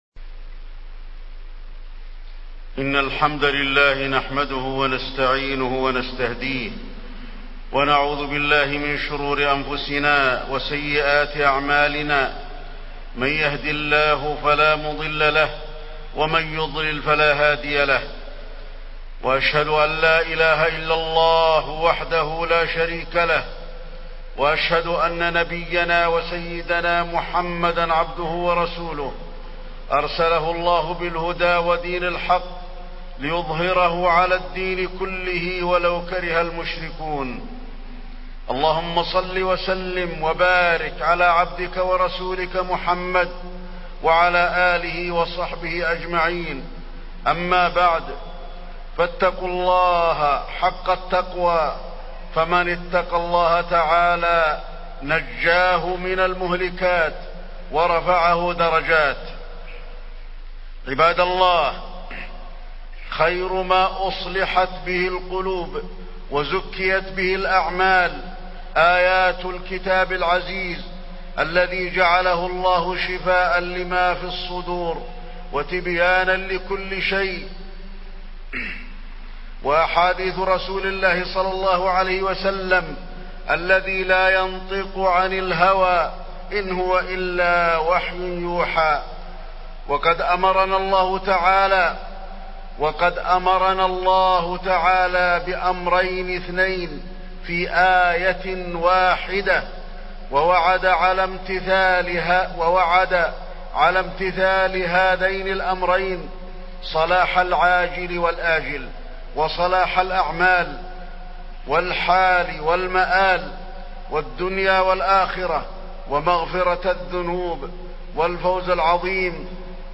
تاريخ النشر ٢ جمادى الآخرة ١٤٢٩ هـ المكان: المسجد النبوي الشيخ: فضيلة الشيخ د. علي بن عبدالرحمن الحذيفي فضيلة الشيخ د. علي بن عبدالرحمن الحذيفي التقوى